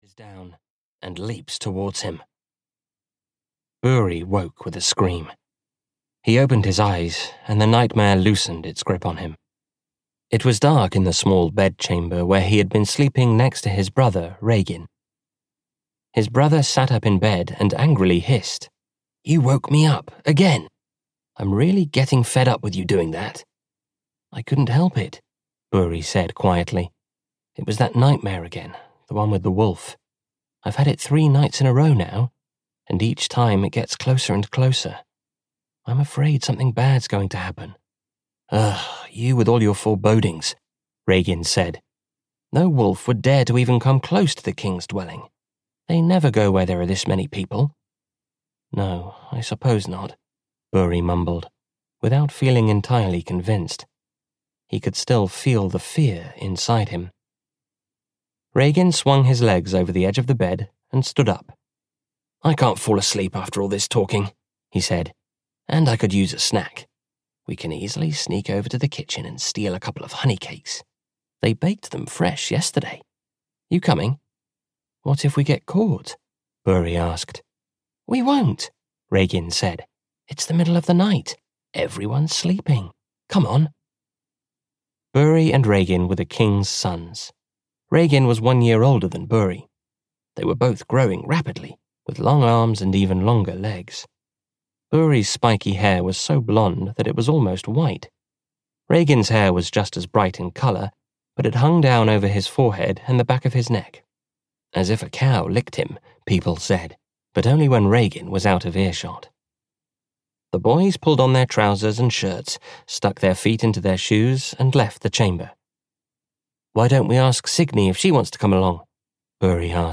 The King's Children (EN) audiokniha
Ukázka z knihy